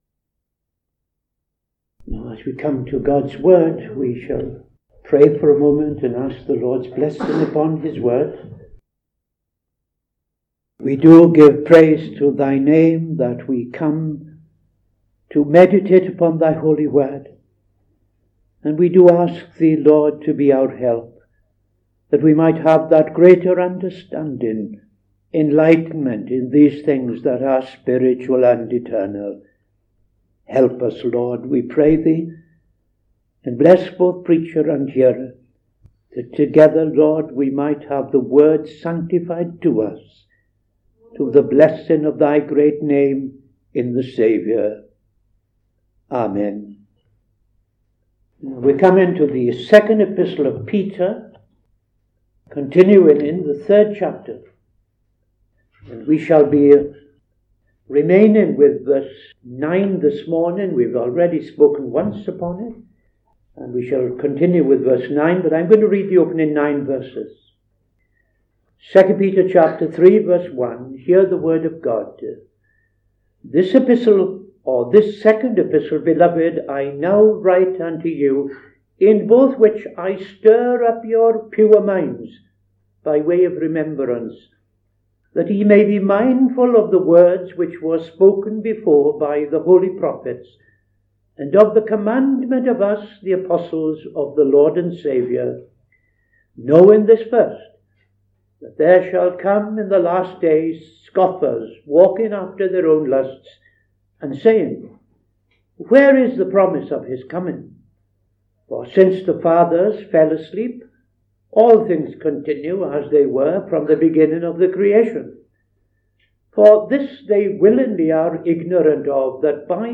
Saturday Sermon - TFCChurch
Opening Prayer and Reading II Peter 3:1-9